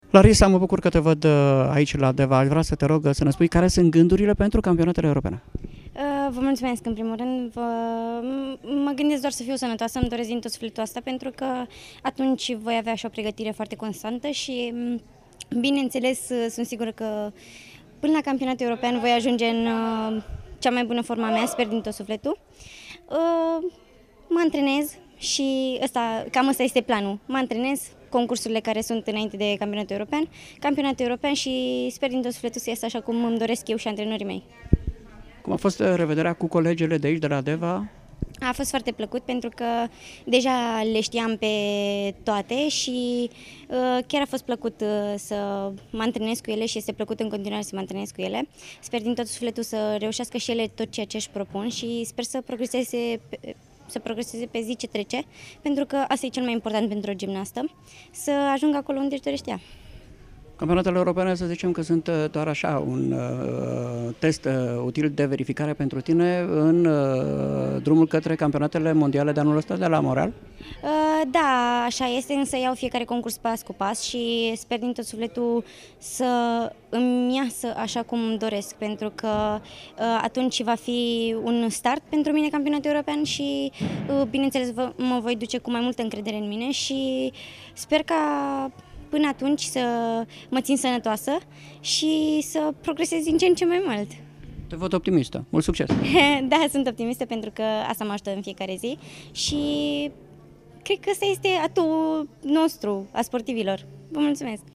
a stat de vorbă cu sportiva în vârstă de 20 de ani